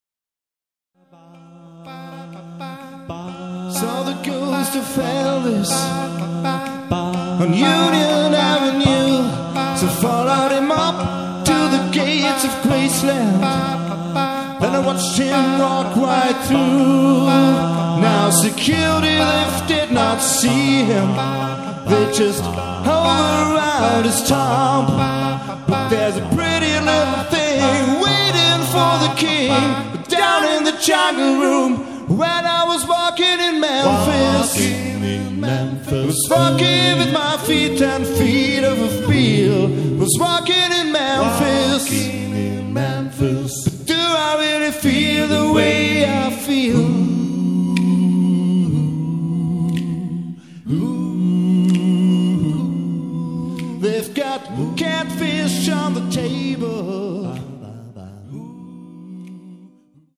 A-Cappella
live & authentisch.